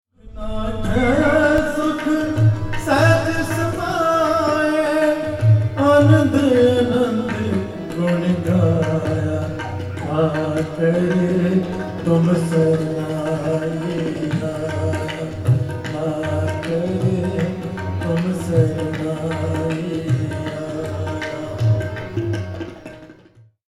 Kirtan
Most services include kirtan, which is hymn-singing. This singing is usually accompanied by musical instruments. It is an important part of Sikh worship.
kirtan.mp3